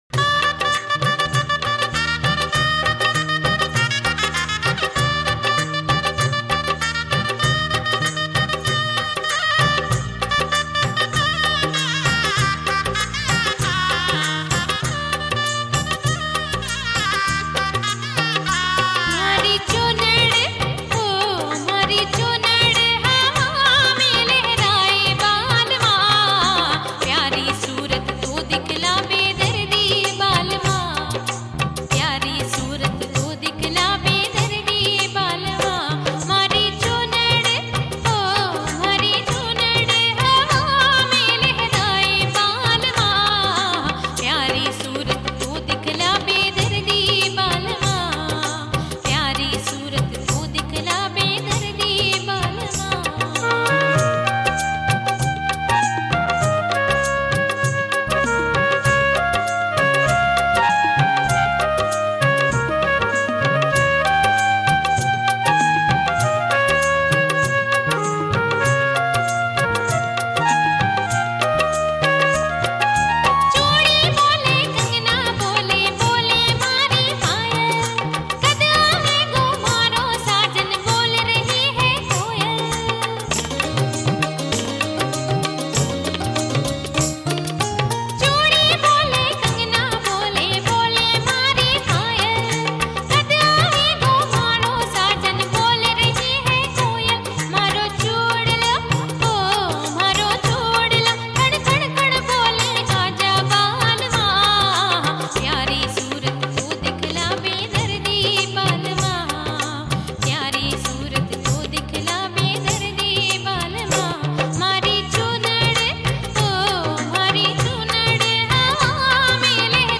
Rajasthani Folk Songs